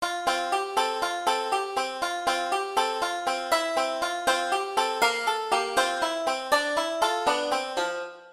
Звуки банджо
Звуковой эффект на банджо